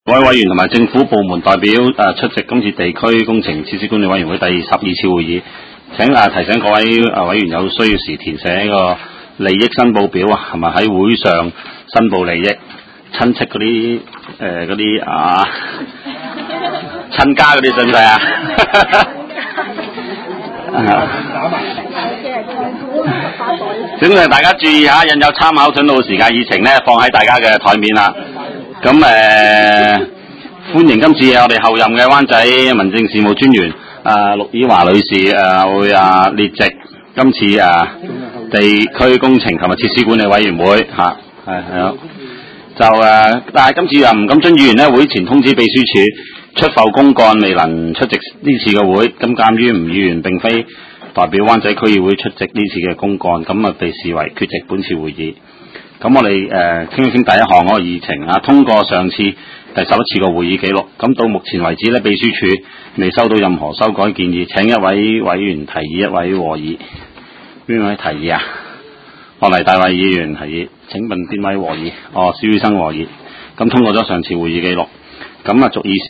地區工程及設施管理委員會第十二次會議
灣仔民政事務處區議會會議室